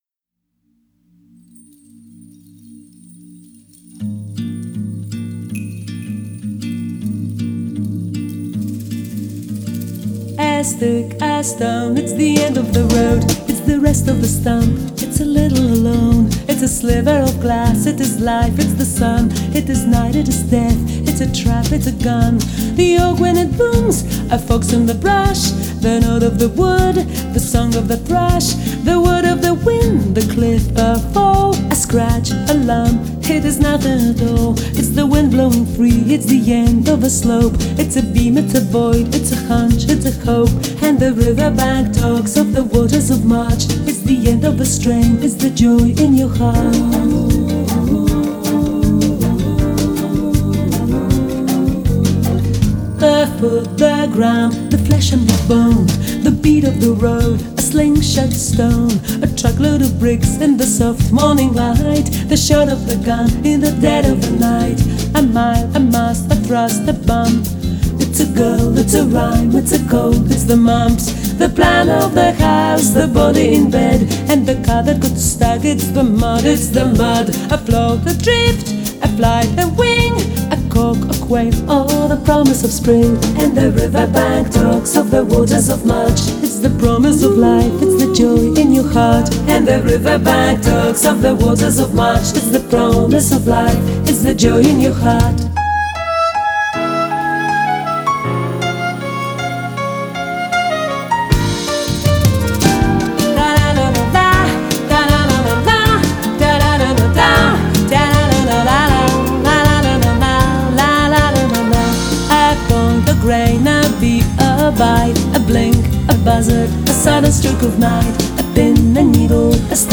■Ladies' Jazz■